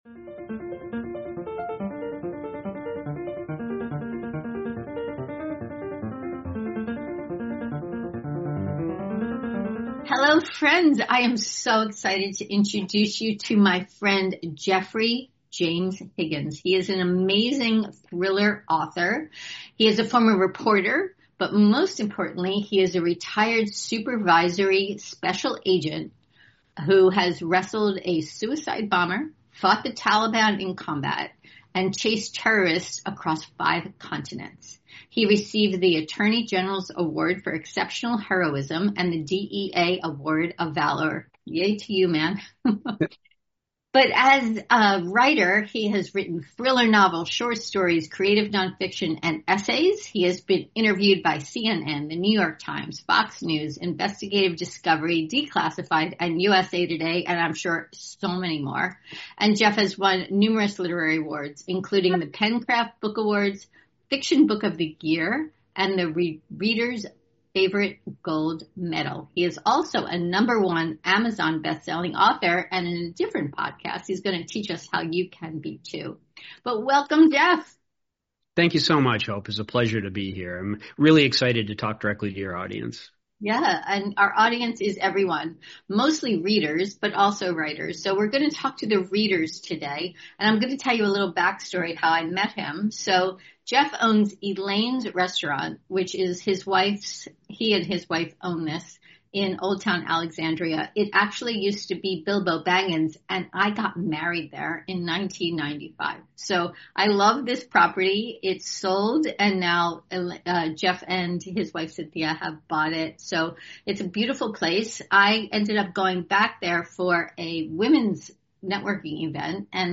This new show — live from the popular bistro Elaine’s in Old Town, Alexandria VA.